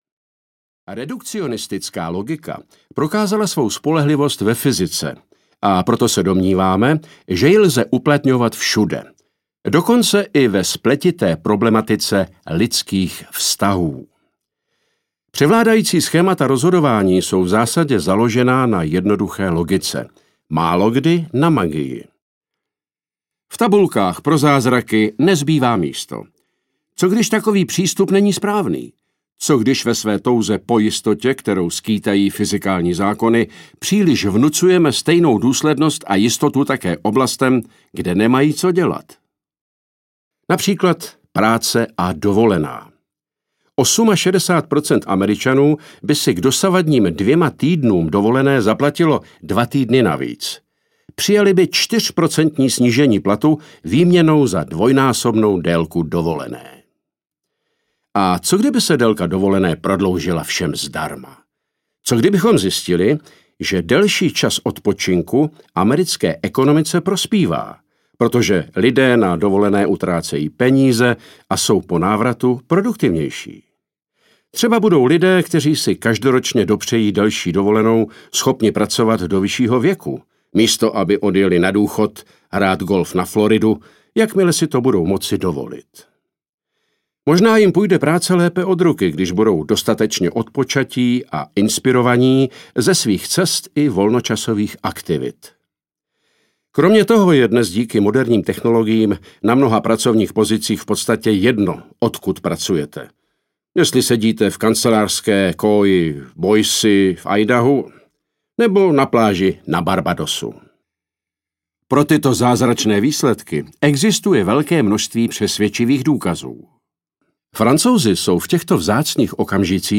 Alchymie audiokniha
Ukázka z knihy